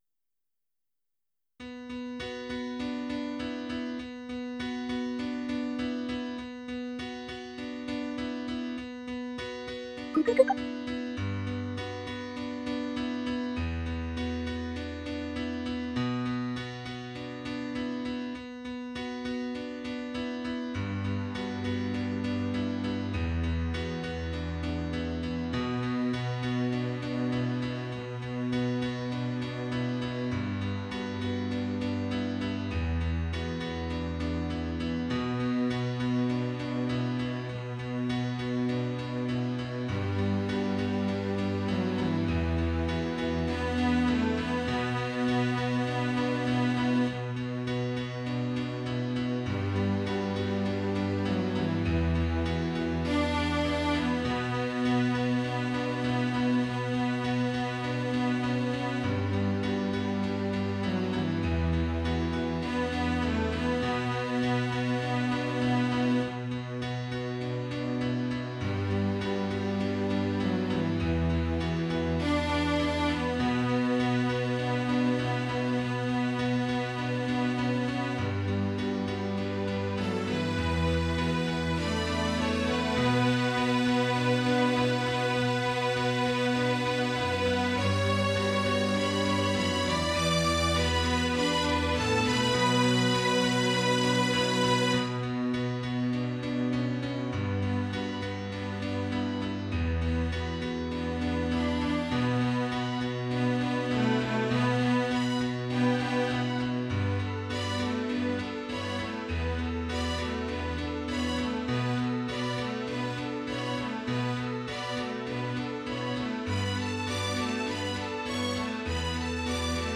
Tags: Quartet, Piano, Strings